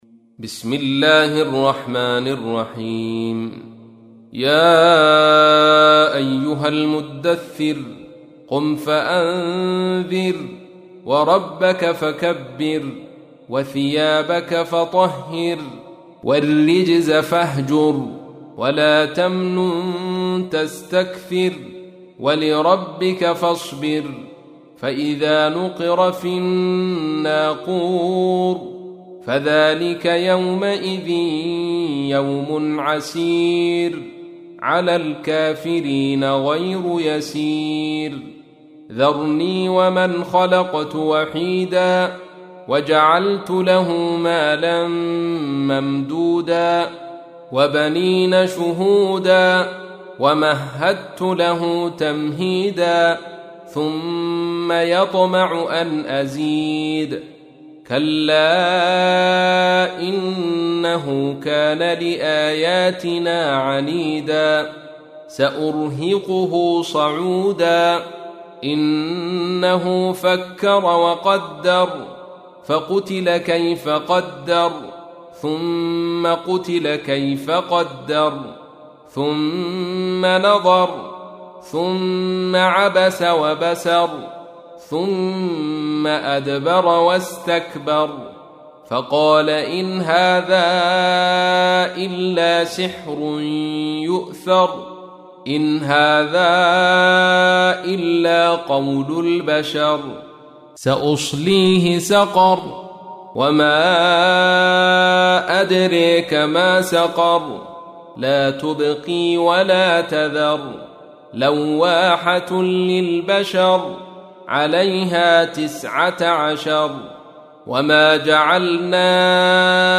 تحميل : 74. سورة المدثر / القارئ عبد الرشيد صوفي / القرآن الكريم / موقع يا حسين